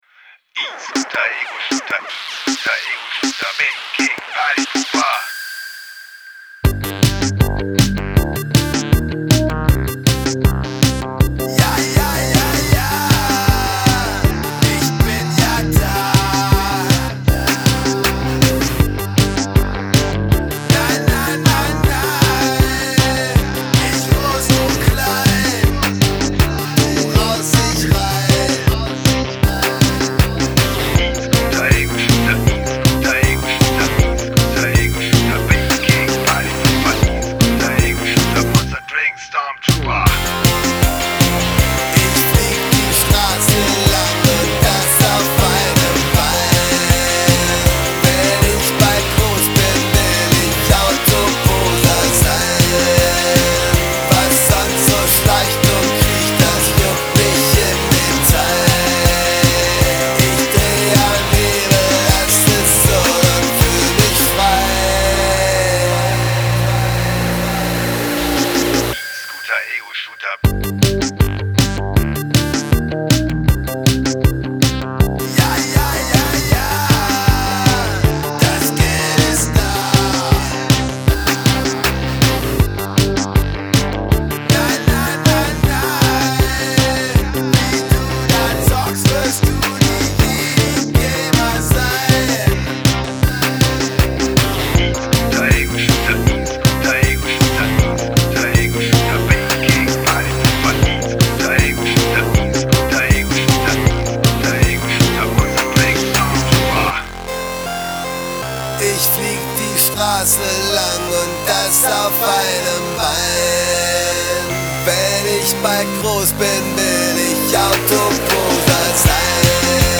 LoFi-Elektro-NDW-Plastik-Punk-Daddelautomaten-Pop